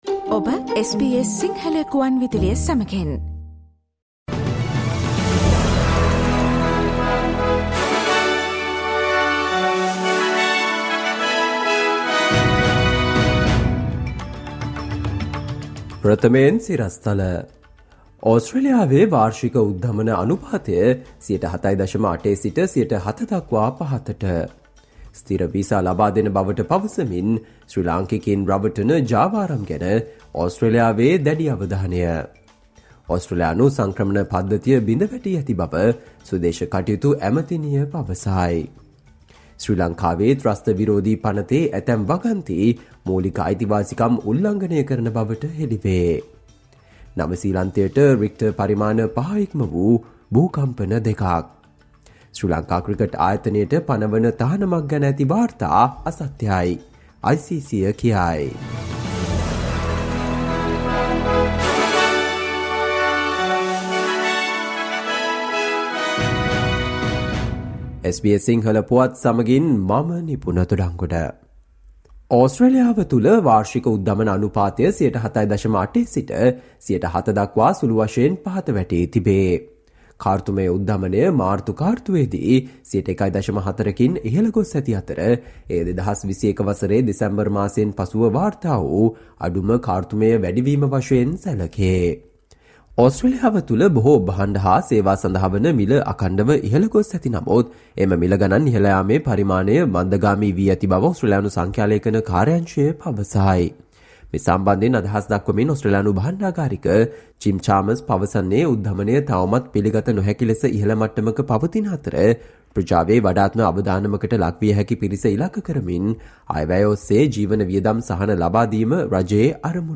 ඕස්ට්‍රේලියාවේ නවතම පුවත් රැගත් SBS සිංහල සේවයේ 2023 අප්‍රේල් 27 වන දා බ්‍රහස්පතින්දා වැඩසටහනේ ප්‍රවෘත්ති ප්‍රකාශයට සවන් දෙන්න.